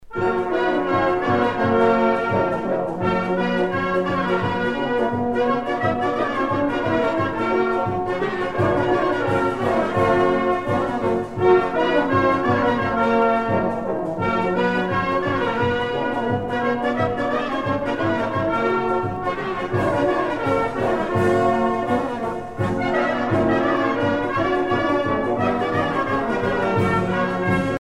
danse : horo (Bulgarie)
Pièce musicale éditée